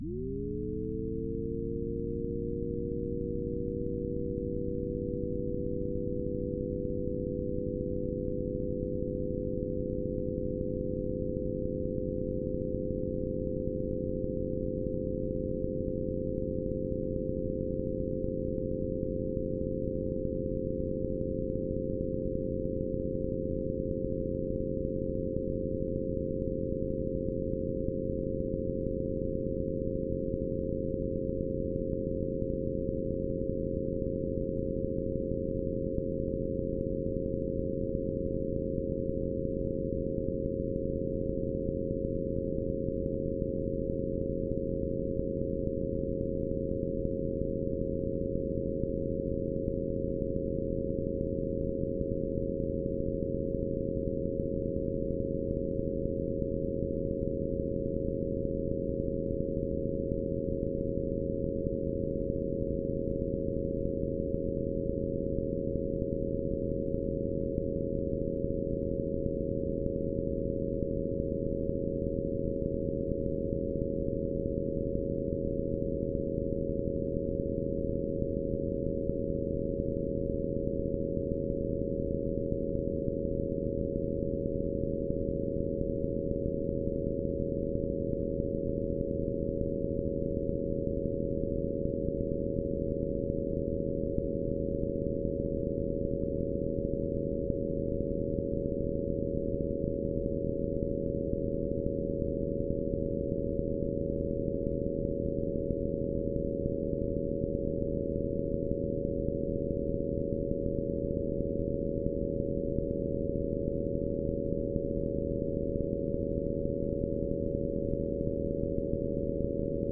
It is interesting to note that these files sound radically different. Over the course of a trillion zeros, the sound of the Z function slowly changes.